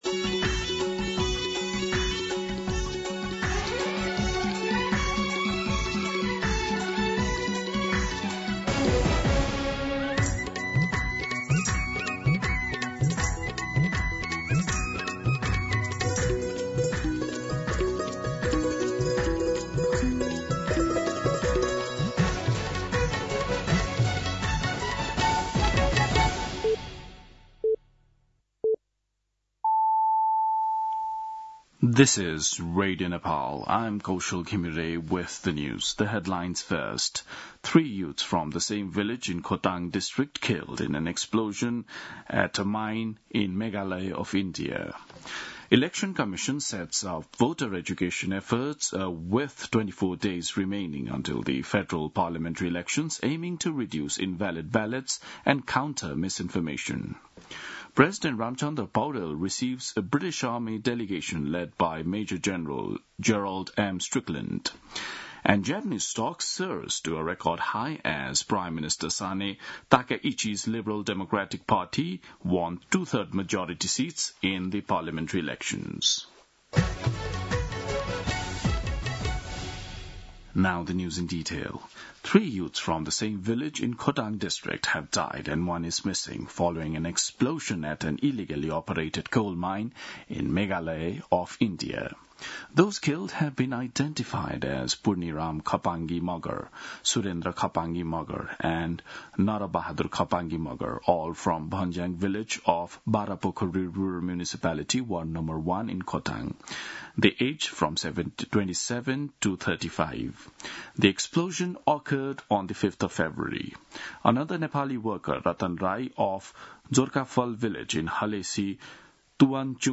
दिउँसो २ बजेको अङ्ग्रेजी समाचार : २६ माघ , २०८२
2pm-English-News-26.mp3